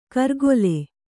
♪ kargole